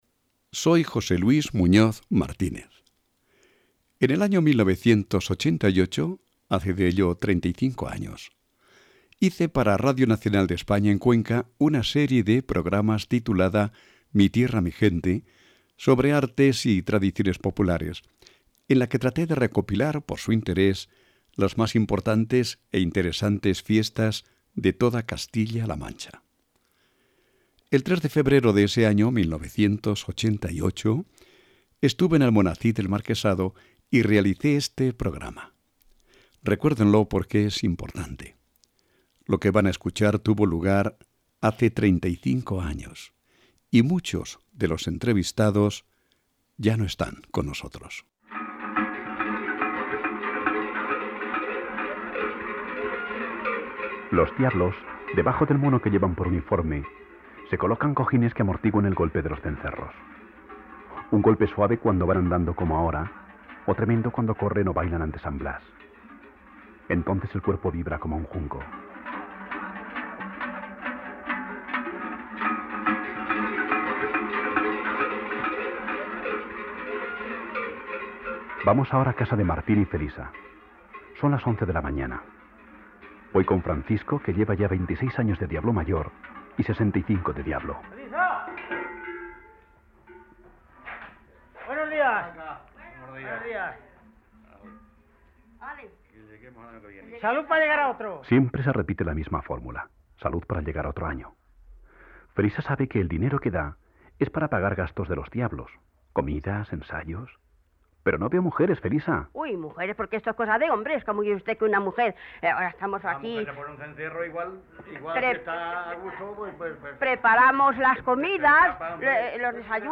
El 3 de febrero del año 1987, hace de ello 35 años, estuve en Almonacid del Marquesado y, este audio, este programa, fue el resultado del trabajo llevado a cabo el día de San Blas.
Programa de radio «Mi Tierra, Mi Gente».